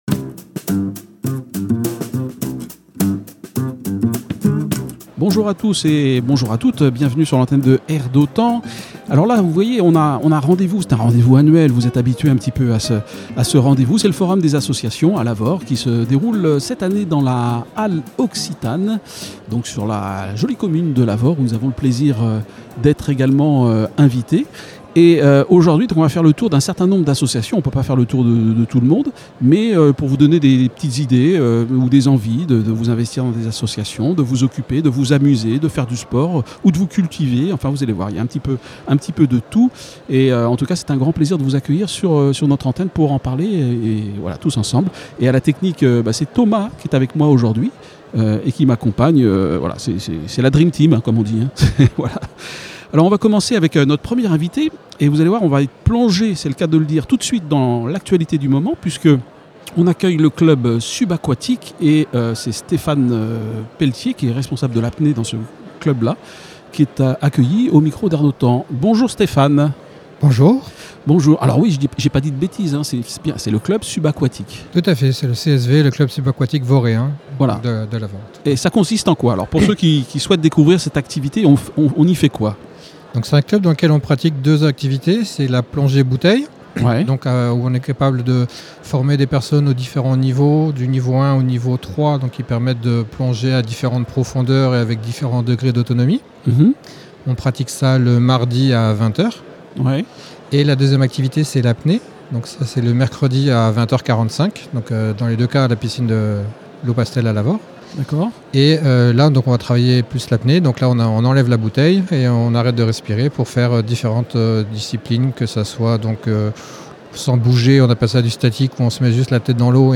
Le CSV diffusé sur la radio R d'autan lors du forum des associations
Interview du CSV lors du Forum des associations